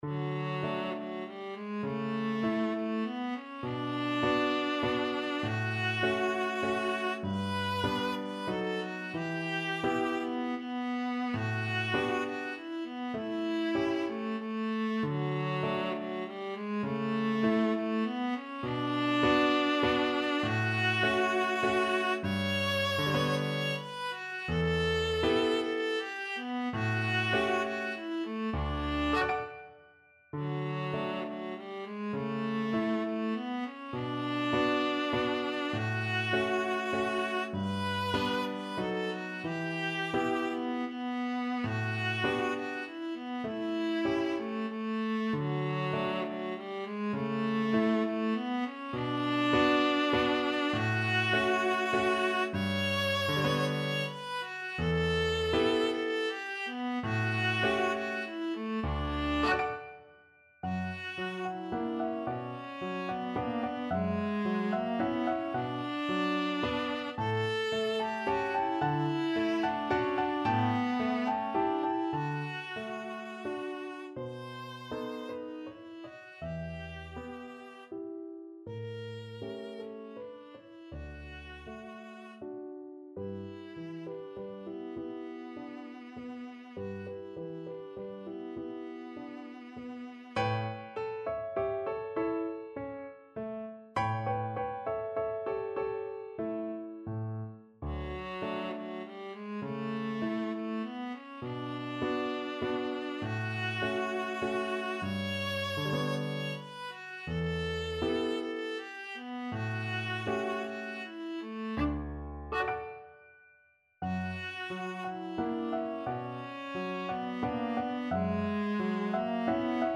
~ = 100 Tranquillamente
3/4 (View more 3/4 Music)
Classical (View more Classical Viola Music)